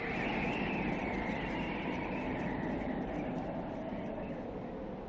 Some Wind.wav